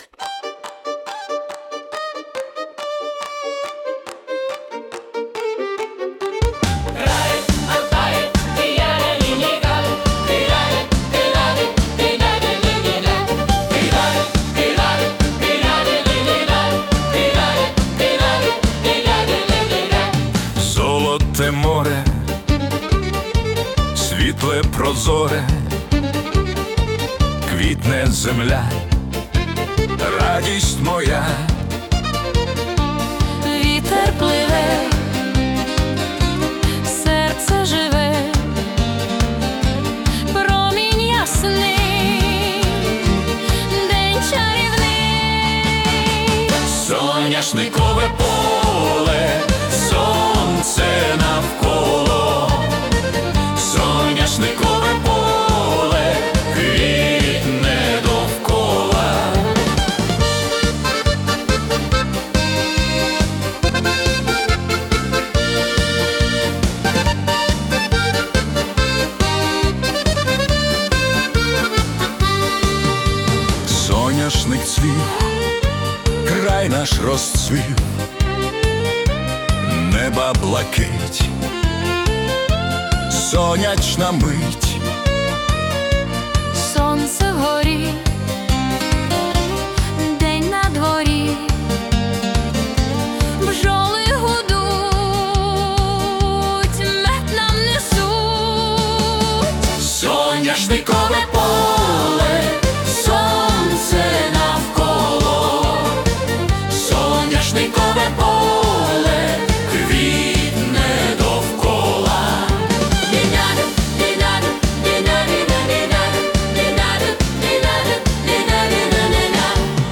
🎵 Жанр: Modern Ukrainian Polka